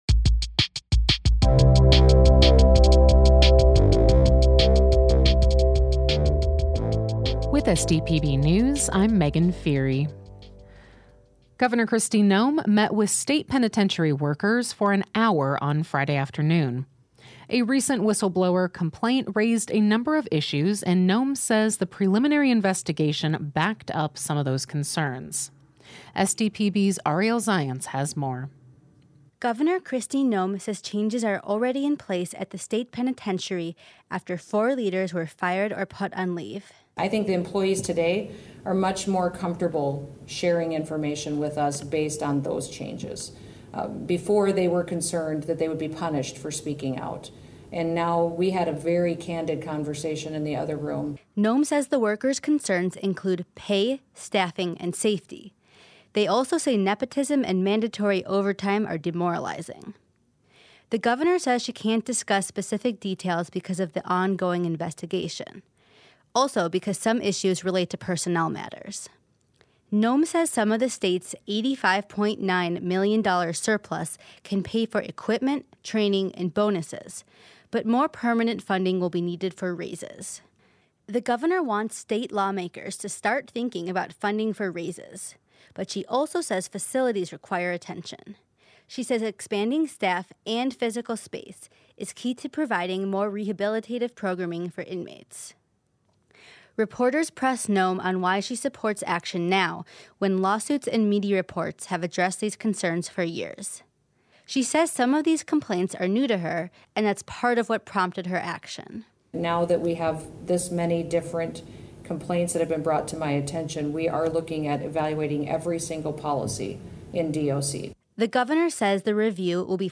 Each day, SDPB's journalism team works to bring you pertinent news coverage.